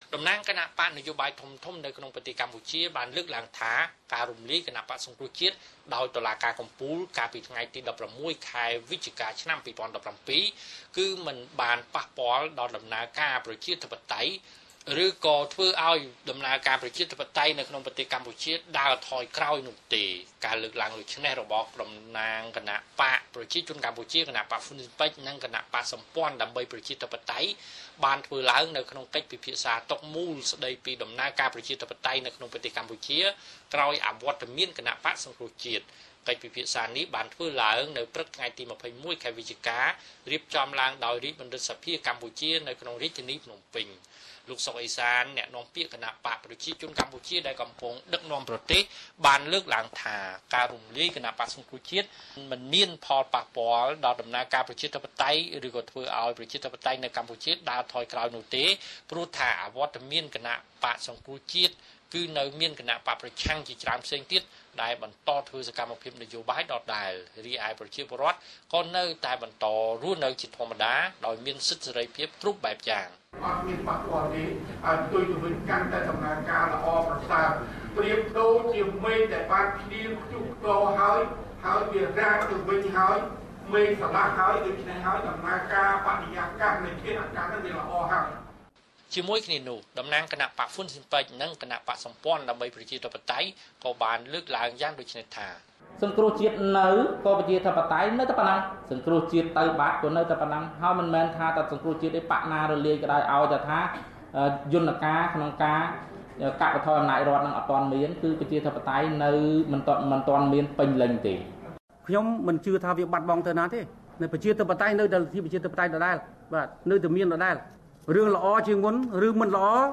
Cambodia political debate after the dissolution of CNRP Source